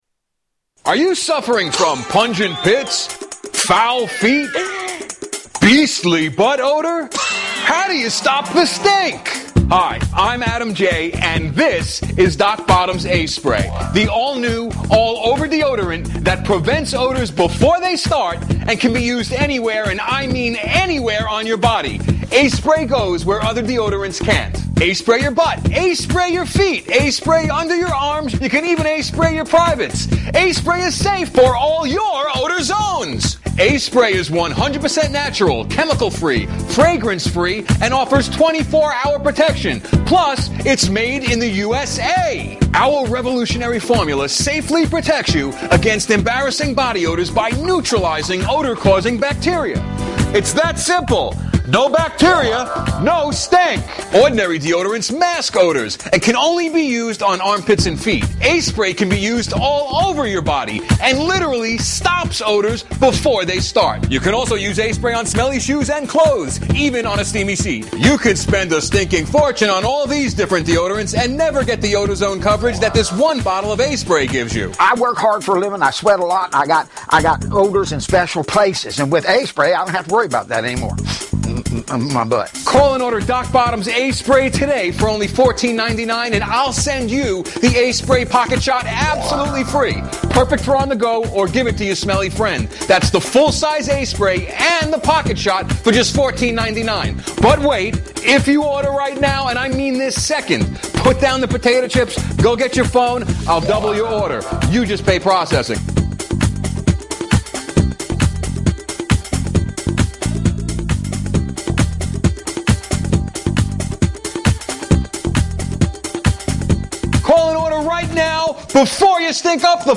AllOver Body Deodorant commercial